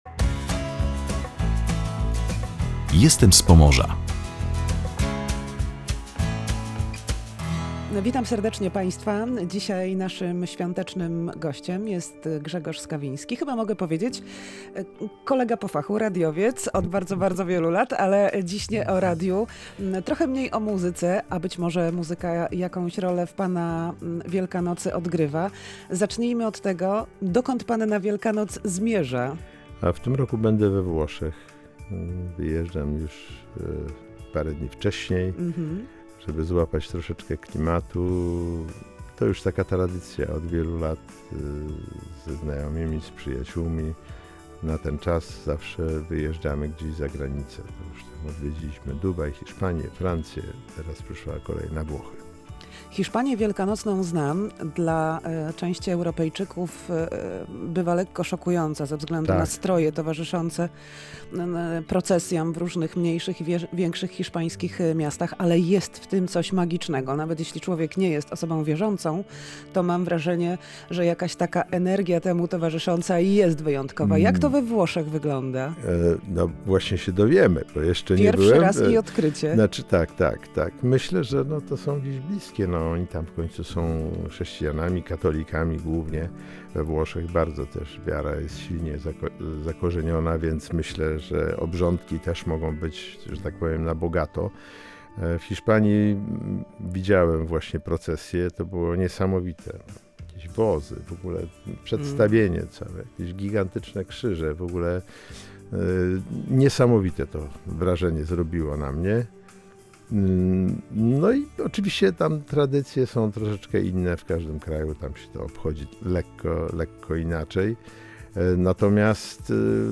Rozmowę udało nam się zrealizować tuż przed wylotem muzyka na święta. Jakie znaczenie mają dla niego świąteczne symbole?